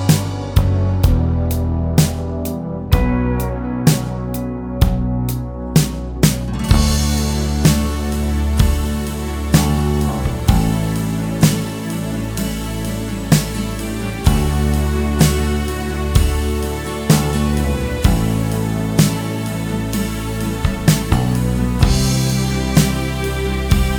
no Solo Guitar Rock 6:47 Buy £1.50